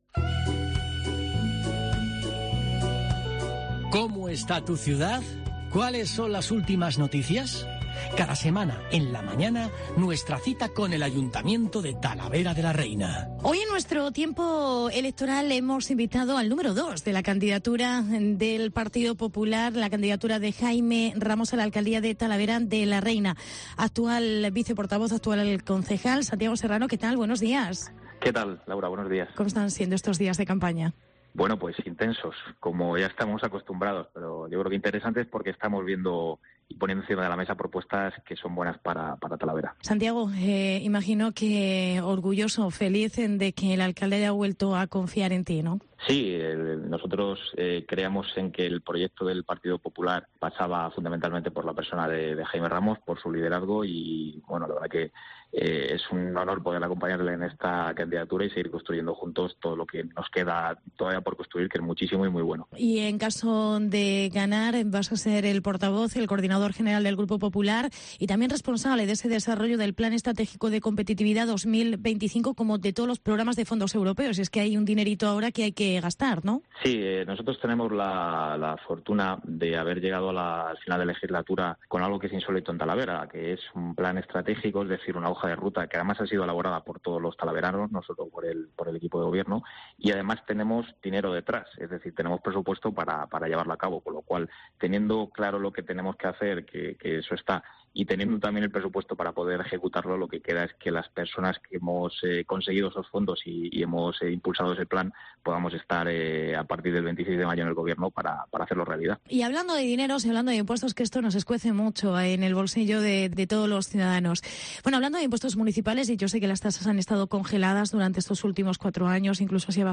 Jubilados/as y viudos/as pagarán el 50% del IBI en Talavera. Entrevista concejal Santiago Serrano